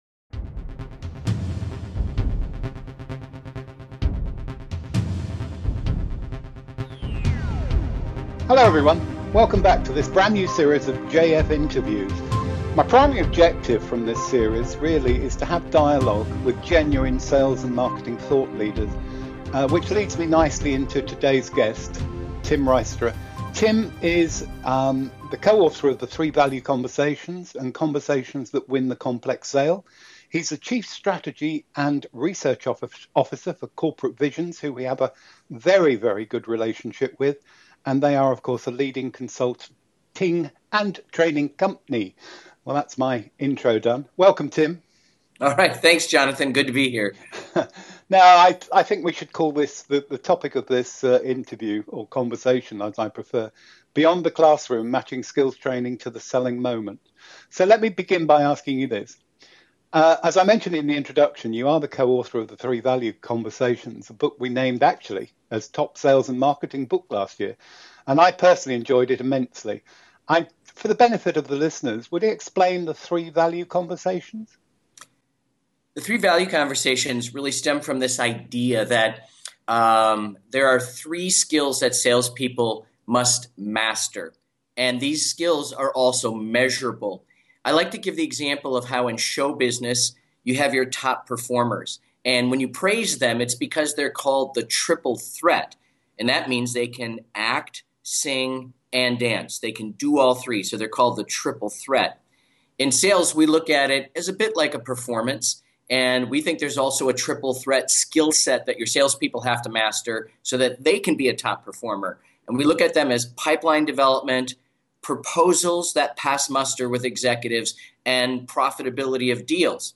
Category: Interview, Sales Management, Sales Training